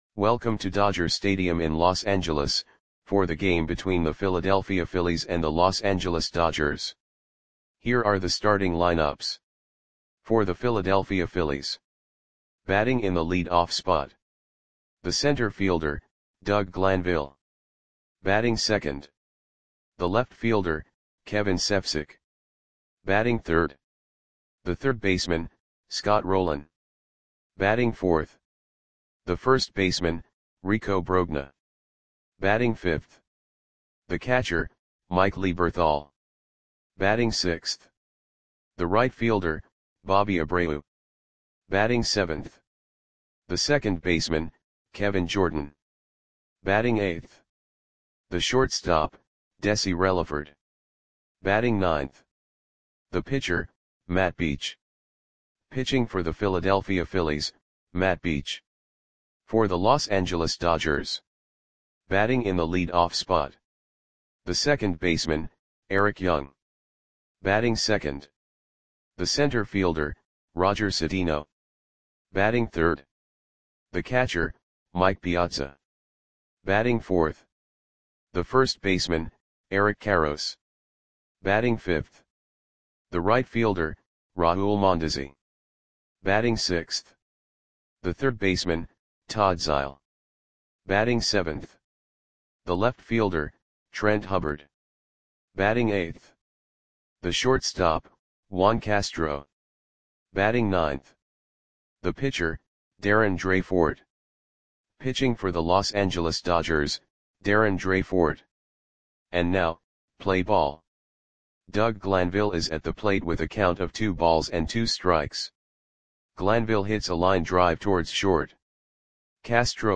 Lineups for the Los Angeles Dodgers versus Philadelphia Phillies baseball game on May 11, 1998 at Dodger Stadium (Los Angeles, CA).
Click the button below to listen to the audio play-by-play.